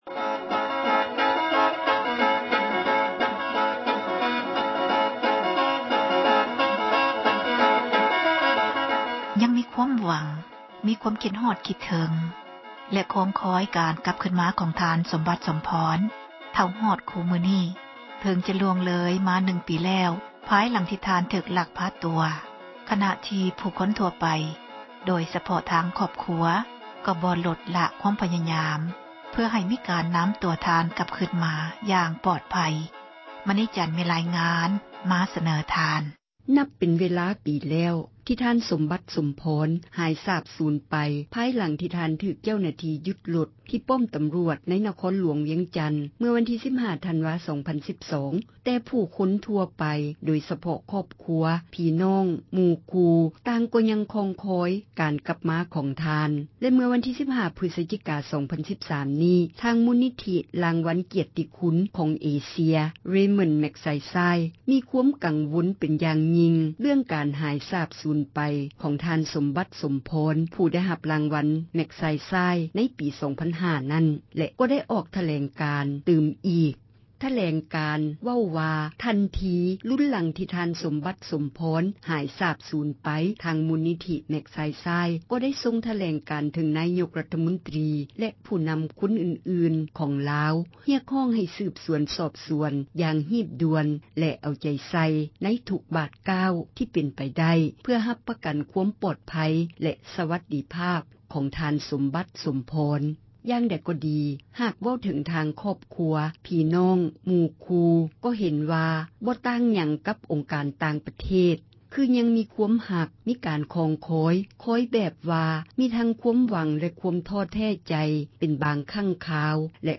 ສັມພາດຫລານທ່ານສົມບັດ